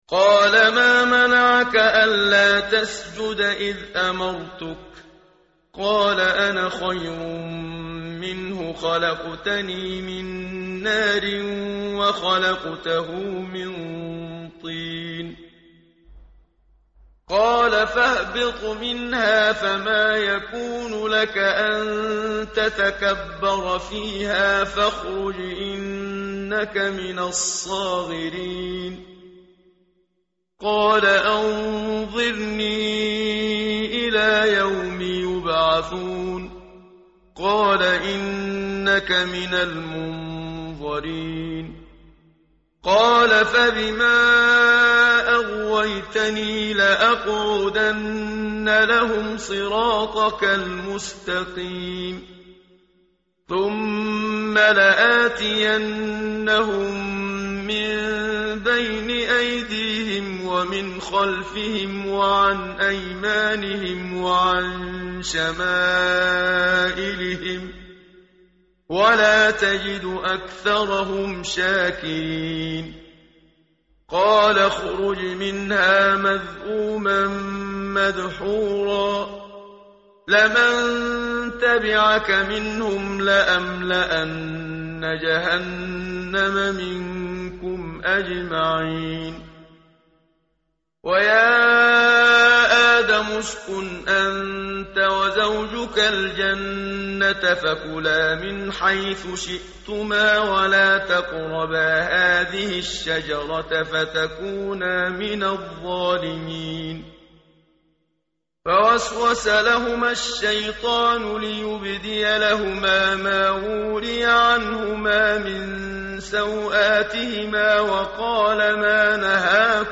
tartil_menshavi_page_152.mp3